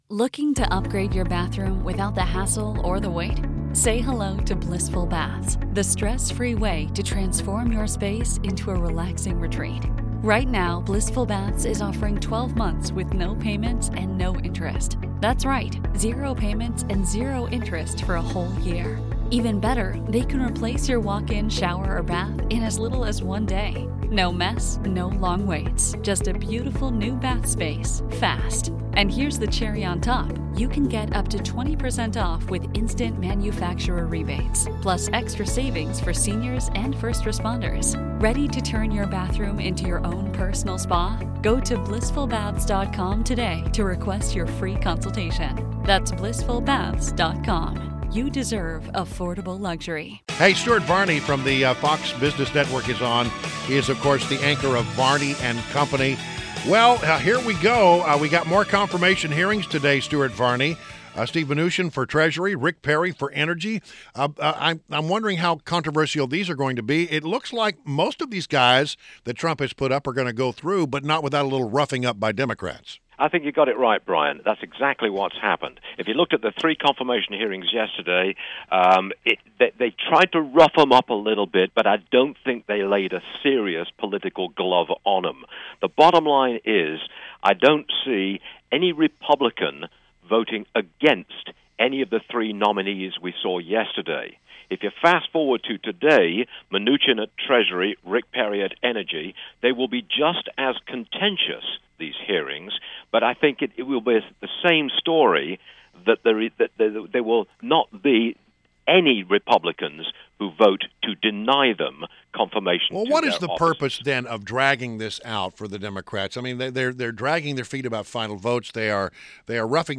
INTERVIEW — STUART VARNEY – Anchor of Varney and Company on Fox Business Network